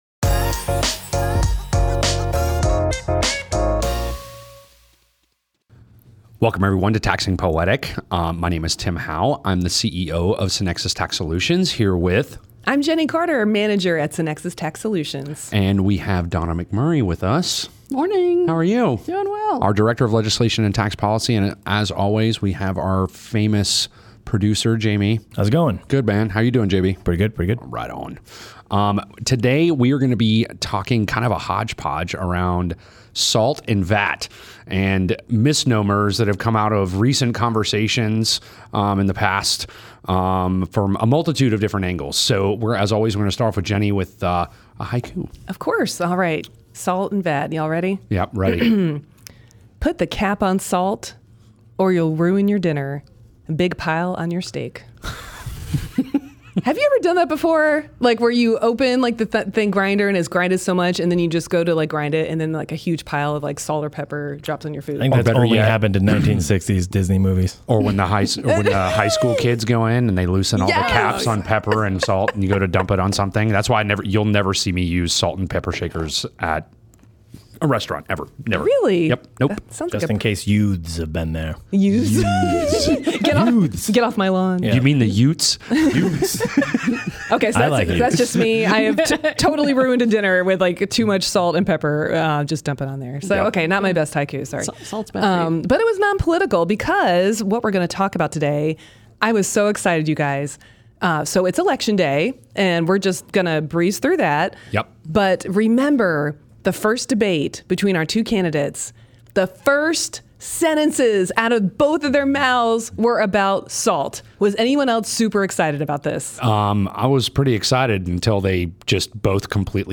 Concerning recent political debates, we discussed how the SALT cap has impacted taxpayers, shifting property values, and taxpayer migration. We also cover VAT, a national tax widely adopted globally, breaking down its structure, pros, and complexities if implemented in the U.S. Join us for a fresh, relatable look at sales taxes in a conversation packed with knowledge, humor, and even a quiz to wrap up the show!